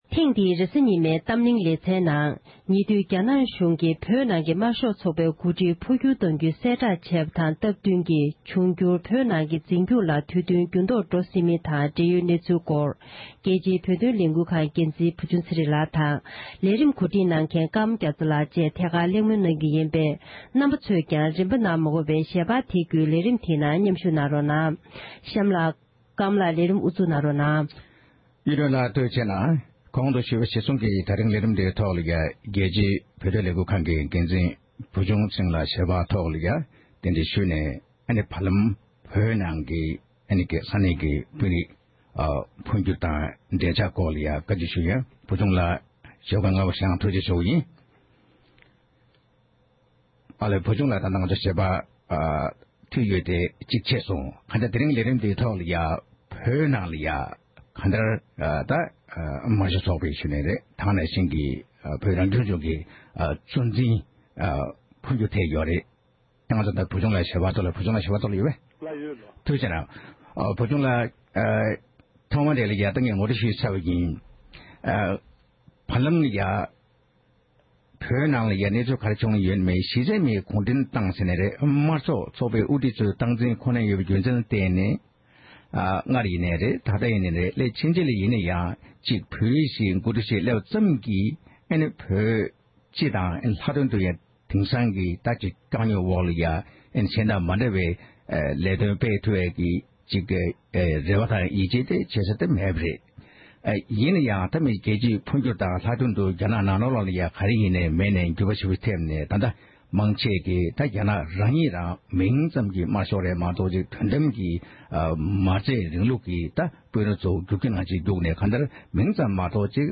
གླེང་མོལ་གནང་བ་ཞིག་གསན་རོགས༎